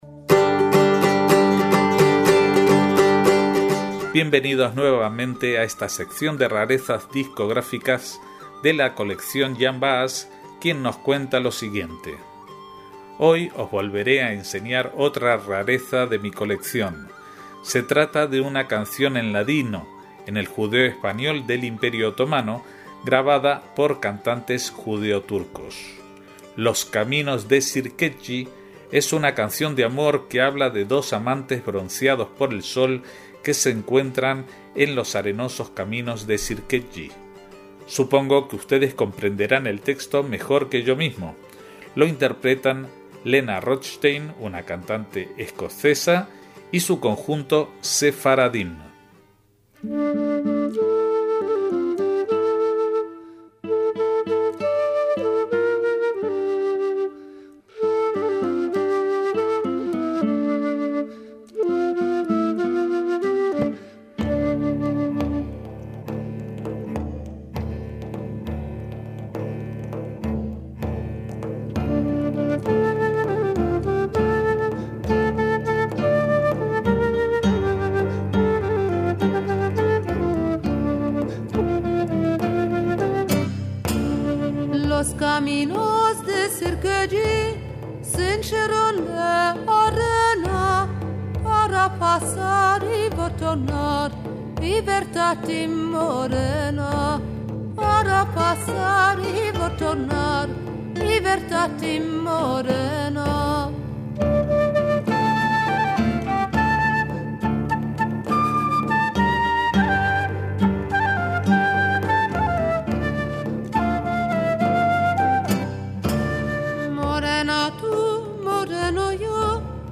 con algunas de las músicas más sorprendentes en su haber, como este intercambio de letras sobre una misma melodía que habla de caminos, entre el folklore sefardí y del sudeste de Europa.